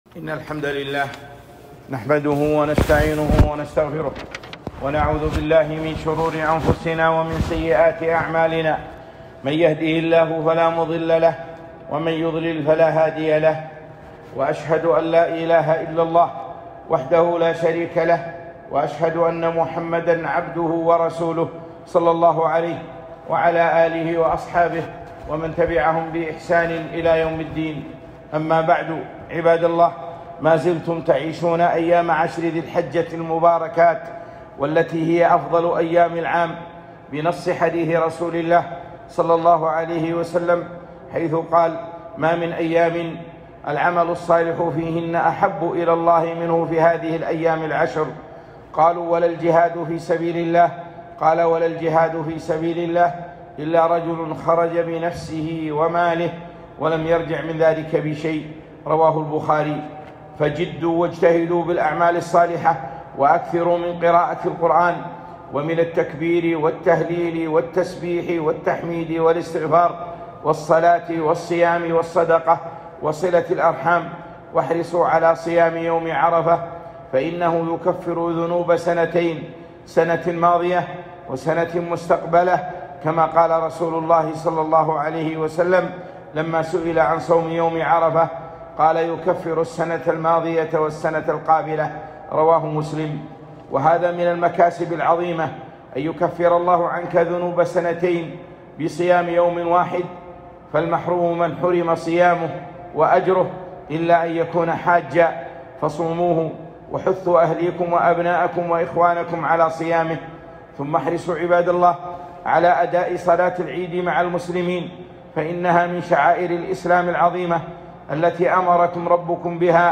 خطبة - عيد الأضحى والأضحية